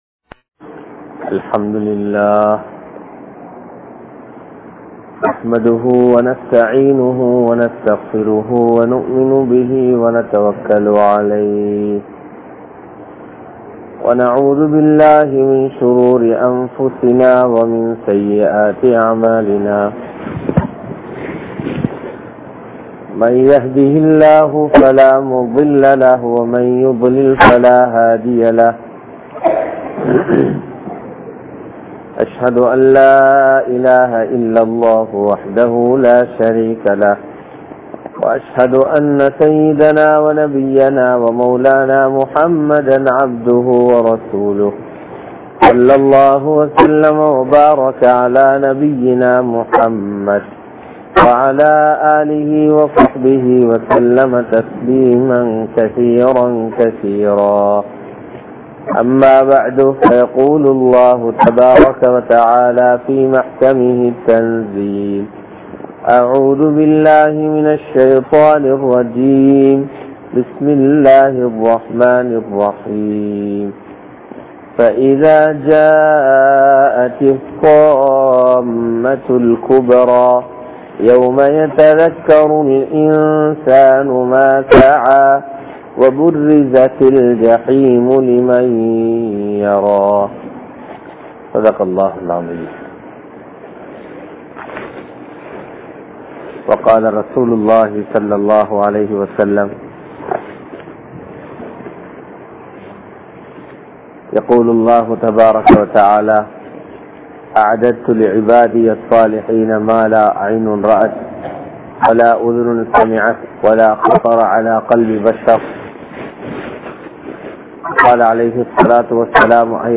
Pirachchinaihalukku Theervu Thawba (பிரச்சினைகளுக்கு தீர்வு தௌபா) | Audio Bayans | All Ceylon Muslim Youth Community | Addalaichenai
Pangaragammana Jumua Masjidh